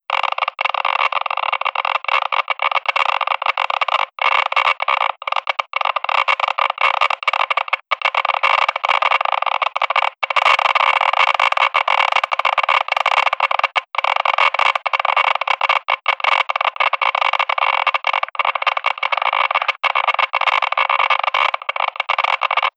geigercounter.wav